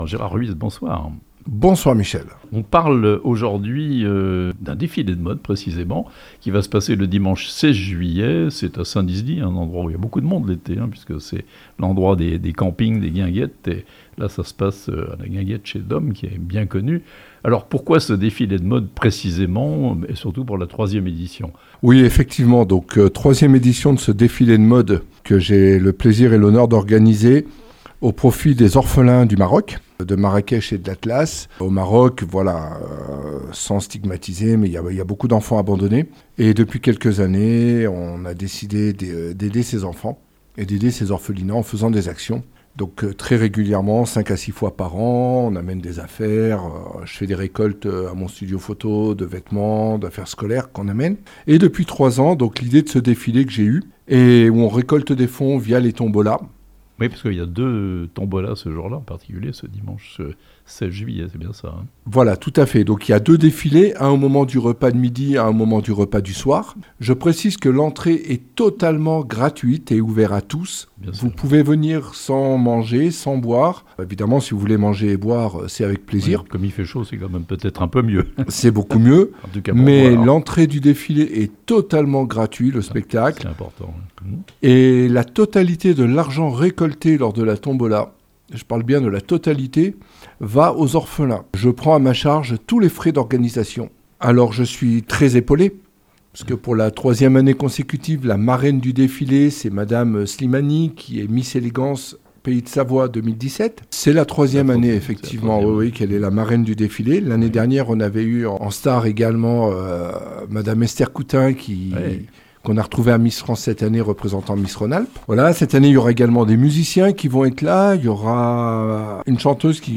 Un défilé de mode pour venir en aide aux orphelins du Maroc (interview)